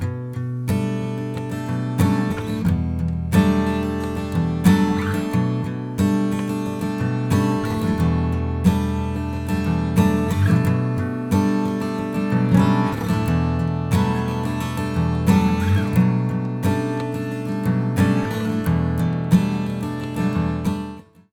Violet Designin Black Knight olisi tuota hintaluokkaa, ääninäytettä akustisen kitaran soitosta ko. mikillä:
Noissa sämpleissä käytetään muuten eri etuasteita, erona mikrofonimuuntaja ja opari, saundi on aika erilainen jos verrataan toisiinsa.